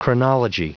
Prononciation du mot chronology en anglais (fichier audio)
Prononciation du mot : chronology